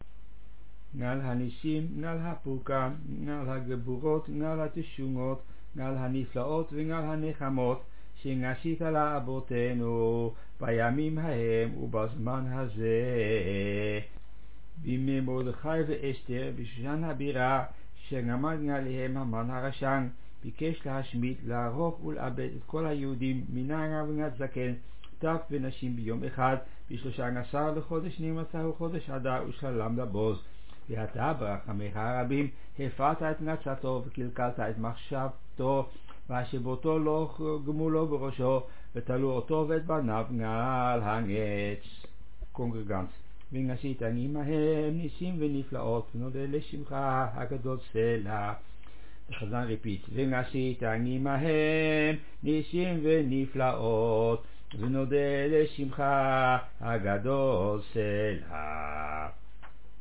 Chazzan
JBS-al hanissim-purim.mp3